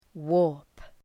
Shkrimi fonetik {wɔ:rp}